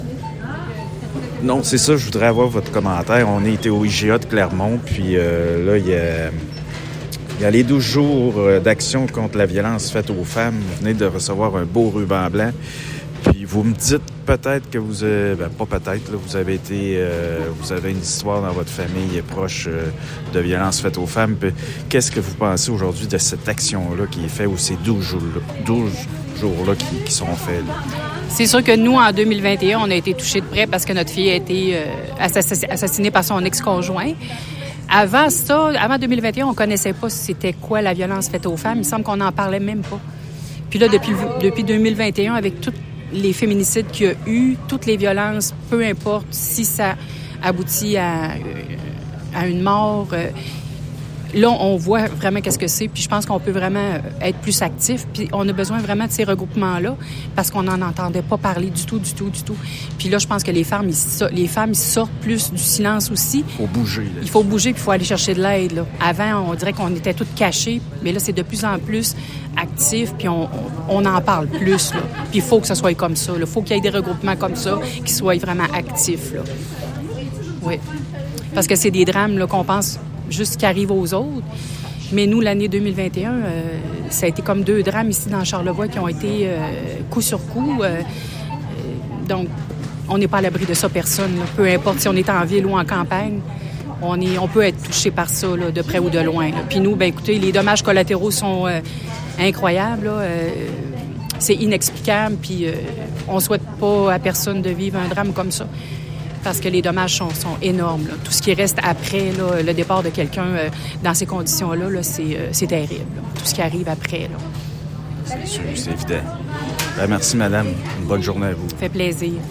Un témoignage émouvant et perçant
Média Photos Charlevoix, présent sur les lieux pour capter l’essence de cette démarche, a pu recueillir l’écho de cette action.